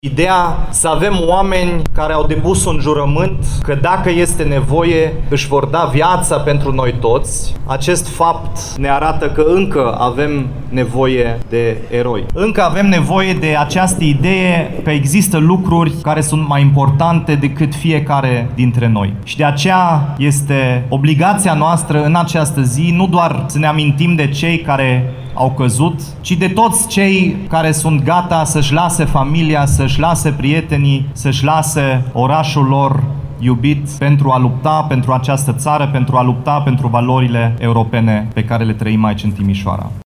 Ziua Eroilor a fost marcată astăzi în fața Catedralei Mitropolitane, unde militarii și angajații structurilor M.A.I. au defilat în onoarea celor căzuţi.
Cu această ocazie, primarul Dominic Fritz a vorbit despre conceptul de „erou”, revenit în actualitate odată cu războiul din Ucraina.
01-Dominic-Fritz.mp3